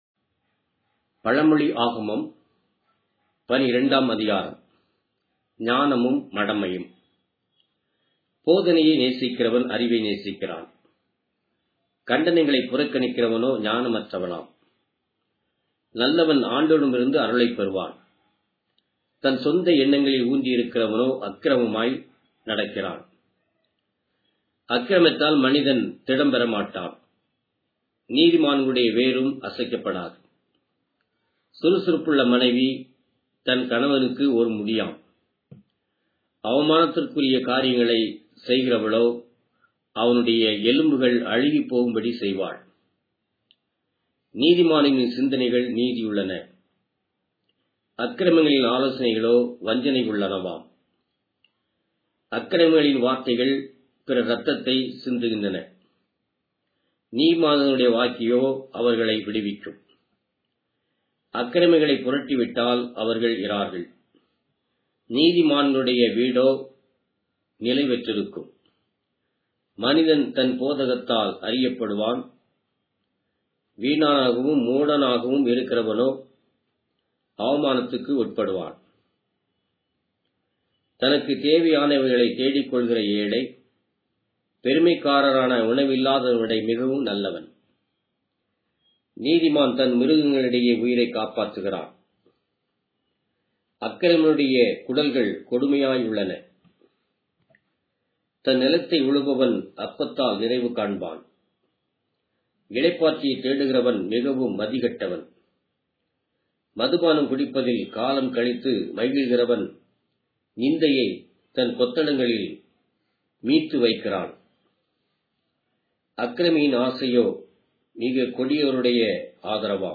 Tamil Audio Bible - Proverbs 7 in Rcta bible version